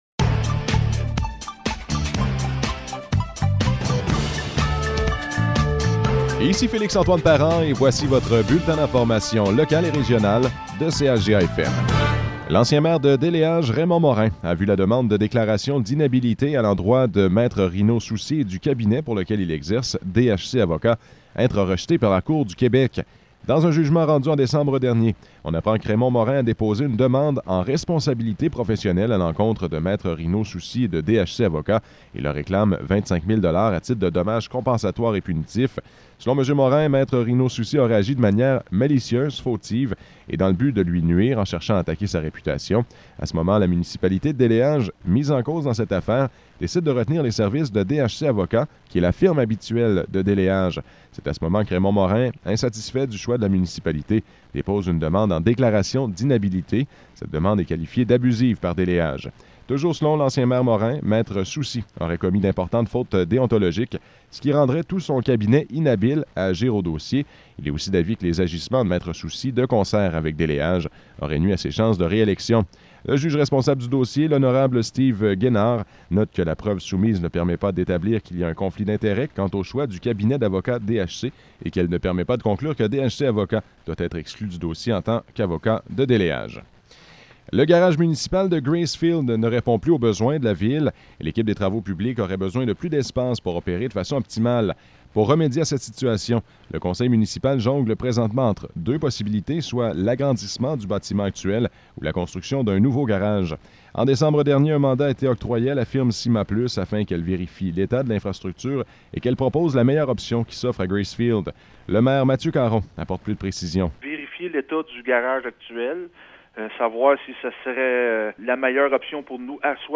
Nouvelles locales - 7 janvier 2022 - 12 h